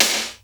TYPICAL SD.wav